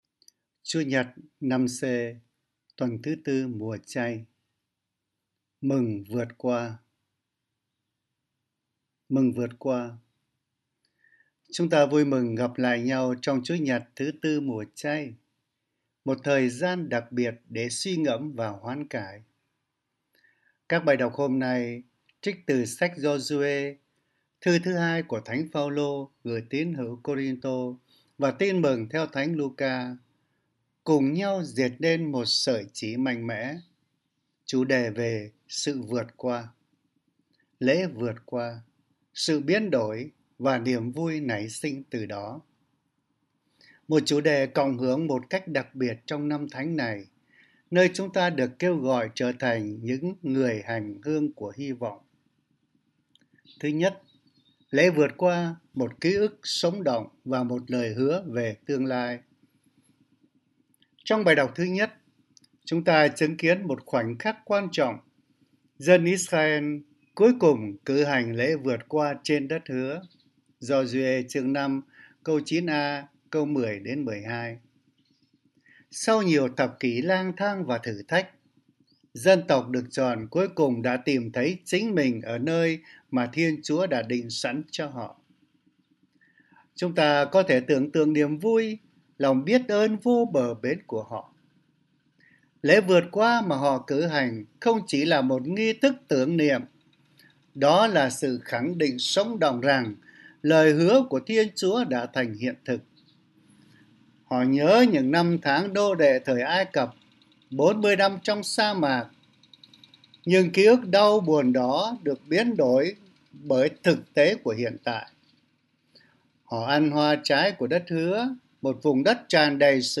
Suy niệm Chúa Nhật